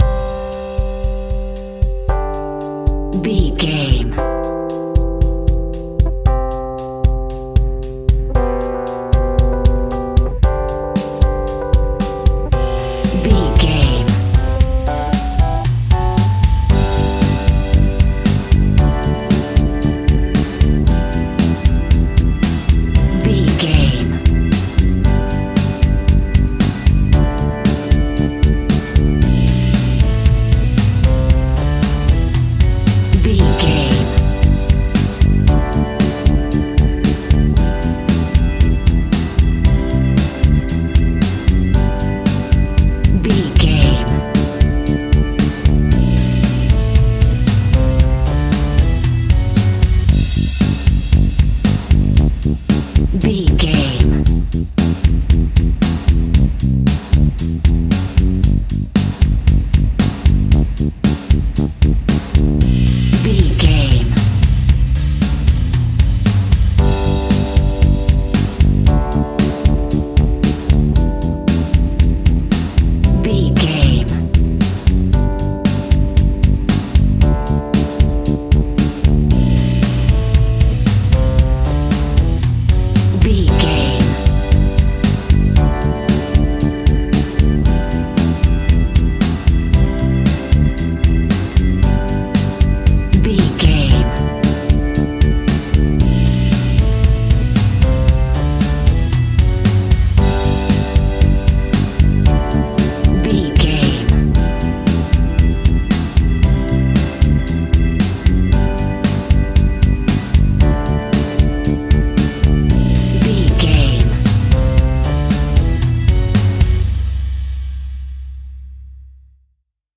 Ionian/Major
fun
energetic
uplifting
cheesy
acoustic guitars
drums
bass guitar
electric guitar
piano
organ